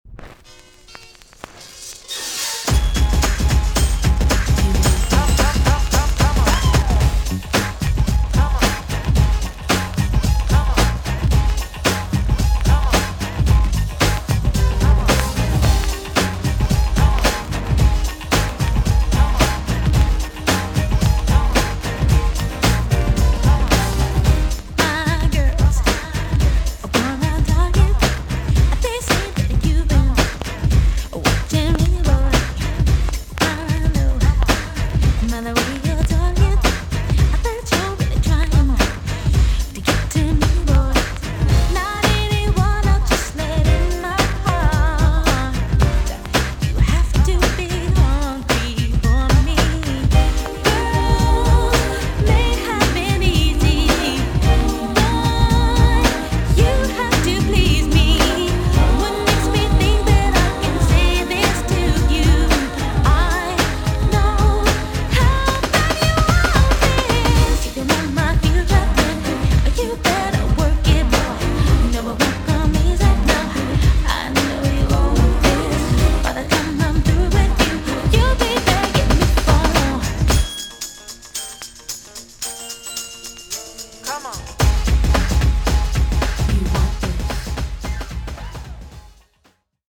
VG+ 少し軽いチリノイズが入ります。
1994 , NICE R&B TUNE!!